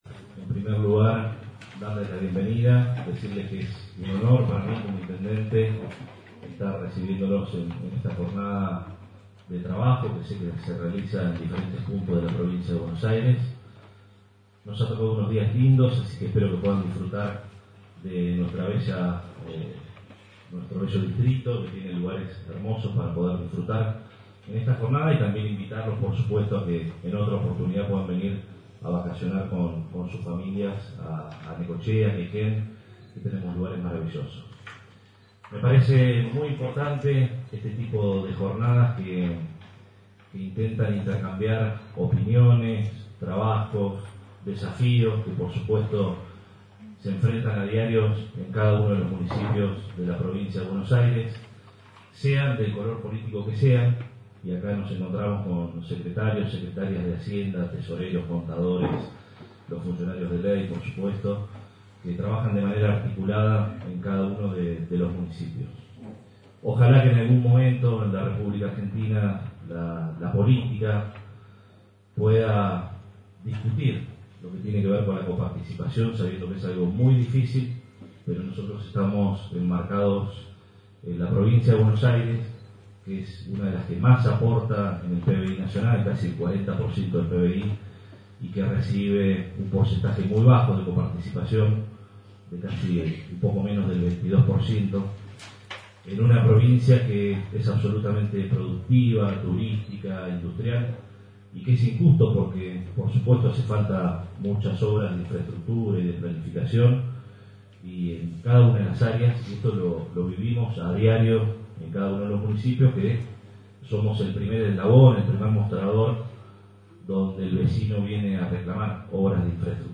En el marco de la 8va Reunión Mensual de Profesionales en Ciencias Económicas del Ámbito Municipal de la provincia de Buenos Aires, el intendente Arturo Rojas brindó las palabras de bienvenida en la apertura del encuentro realizado en la sede de la Delegación Necochea.